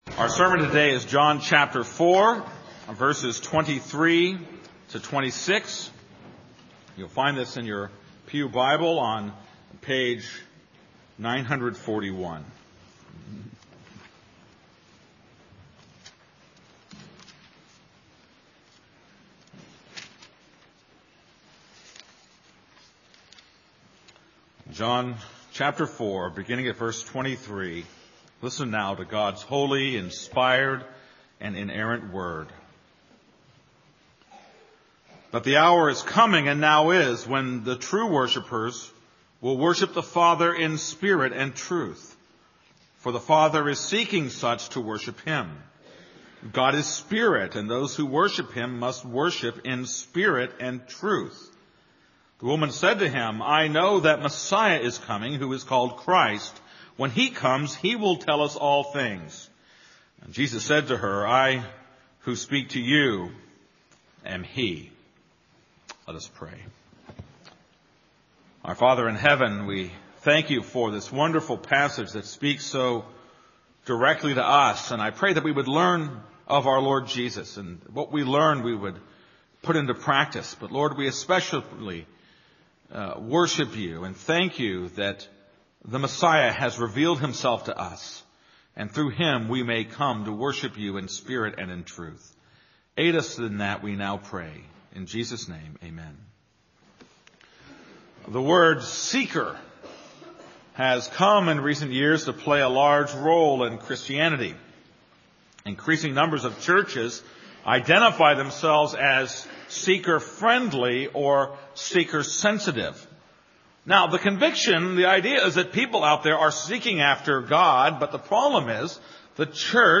This is a sermon on John 4:23-26.